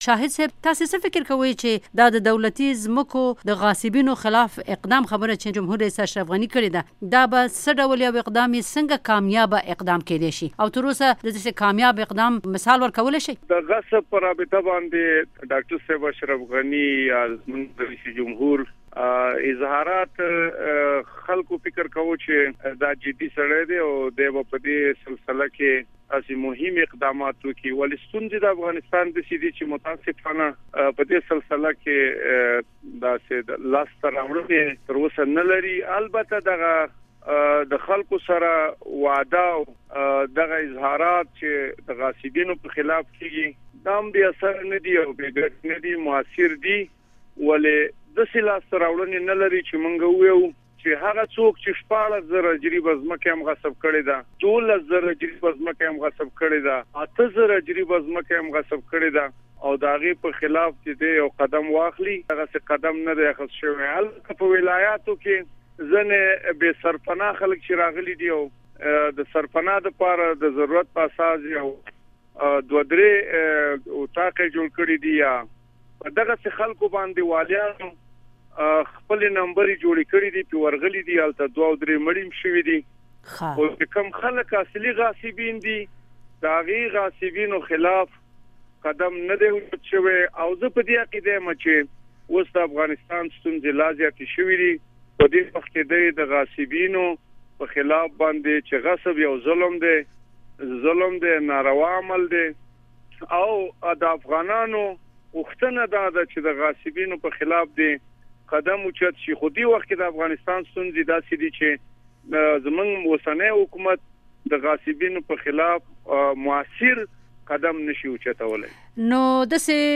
مرکې
د مولوي شاهد سره مرکه